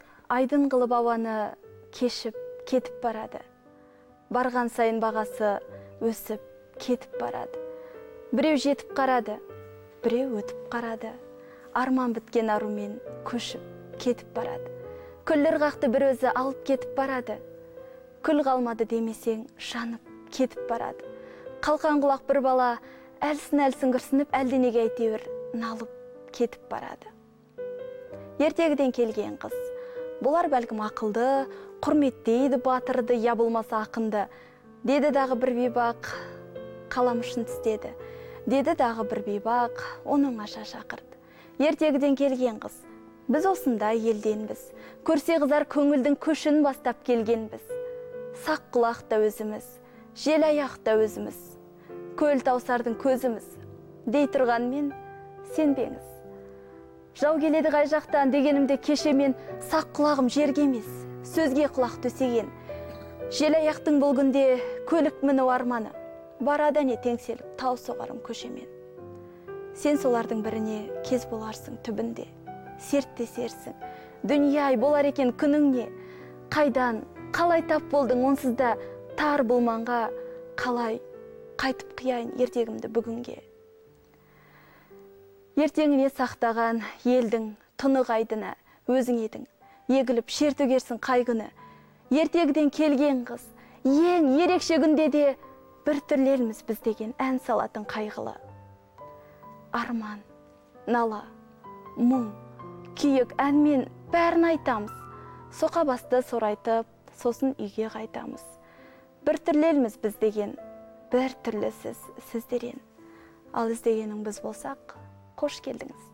«Ертегіден келген қыз» өлеңін оқып берді: